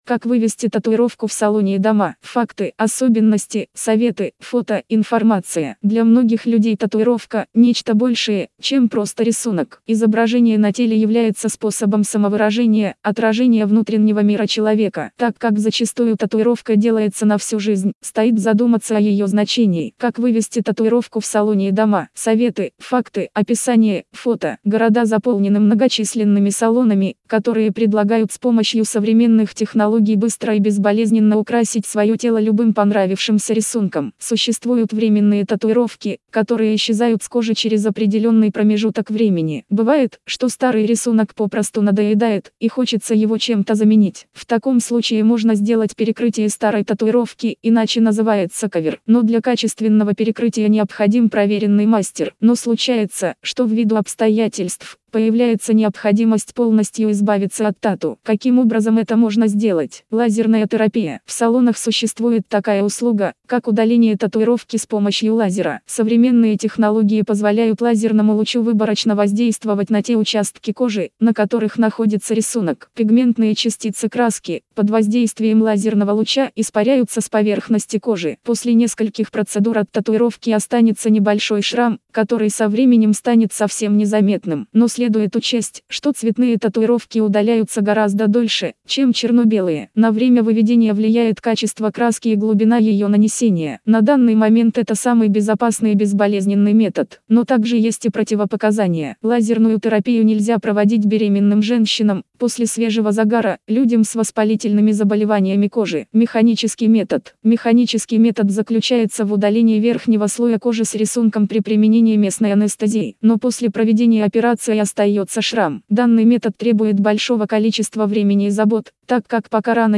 Как-вывести-татуировку-в-салоне-и-дома-аудио-версия-статьи-для-сайта-tatufoto.com_.mp3